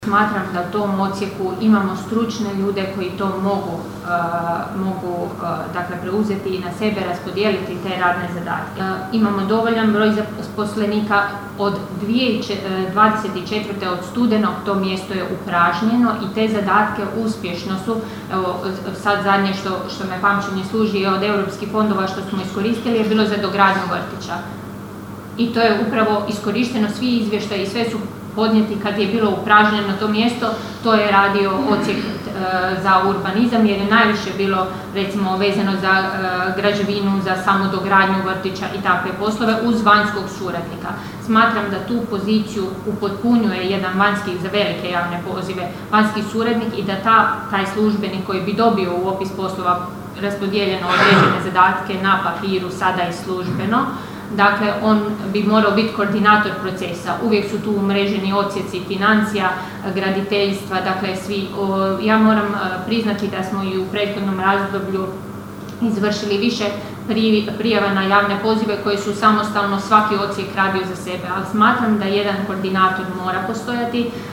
Prijedlog Odluke o II. izmjenama i dopunama Odluke o unutarnjem ustrojstvu i djelokrugu općinske uprave izazvao je žustru raspravu na ovotjednoj sjednici Općinskog vijeća Kršana.
Odgovarajući na dopis sindikalne podružnice, načelnica Ana Vuksan kazala je kako stoji kod svoje odluke: (